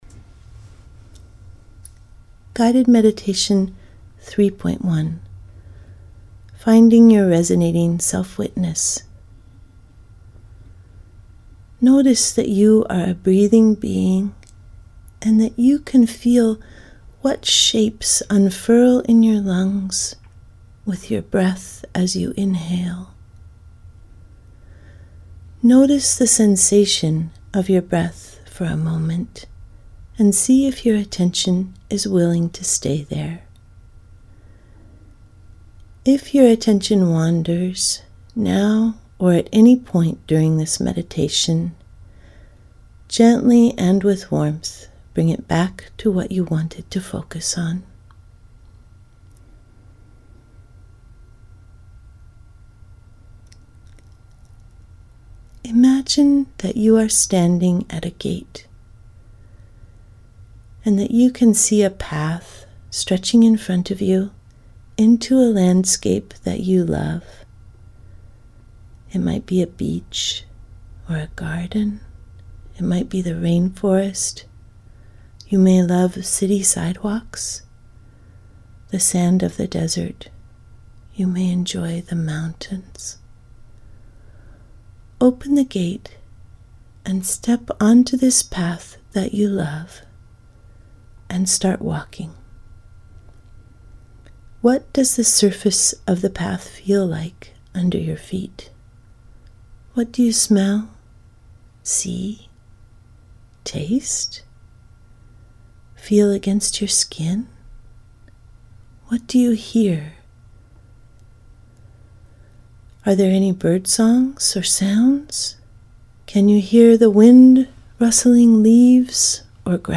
Your Resonant Self Meditations